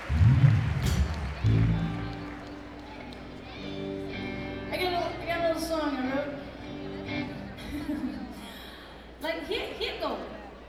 lifeblood: bootlegs: 1995-04-19: memorial auditorium - burlington, vermont (alternate recording 2)
06. talking with the crowd